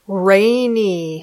Traducción – lluvioso Categoría gramatical – adjetivo Pronunciación – rainy (Haz clic en la palabra para oír la pronunciación.)